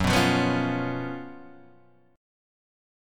F# Major 7th Suspended 4th